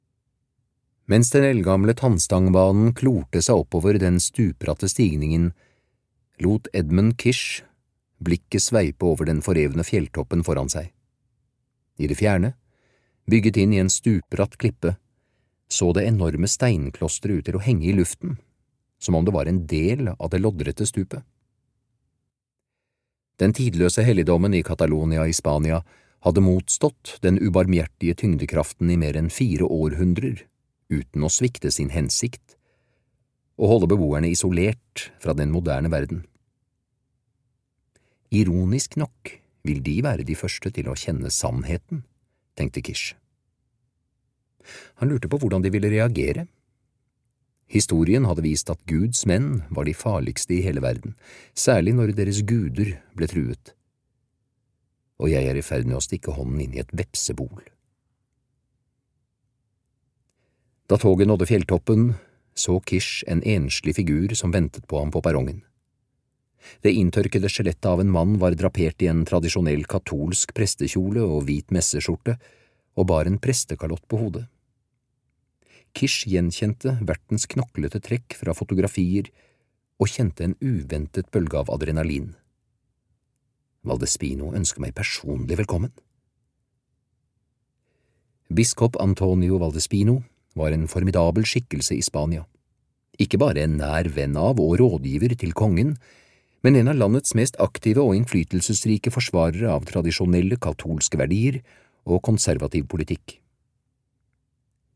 Opprinnelse (lydbok) av Dan Brown